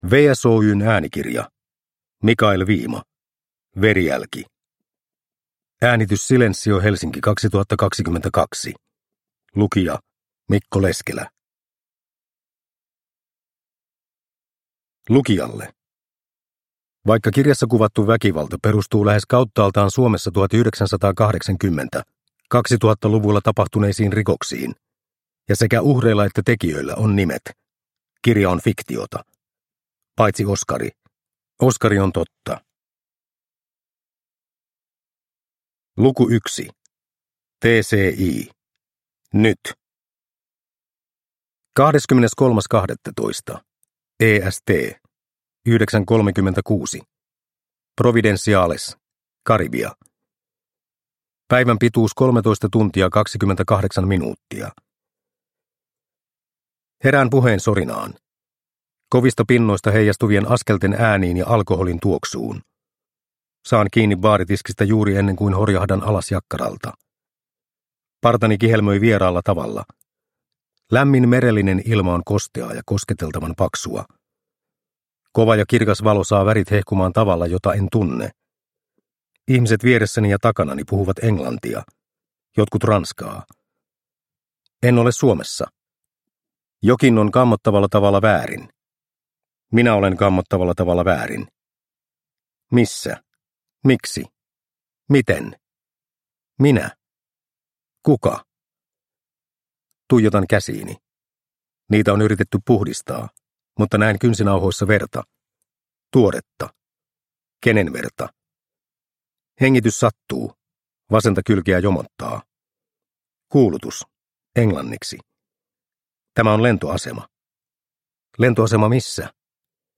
Verijälki – Ljudbok – Laddas ner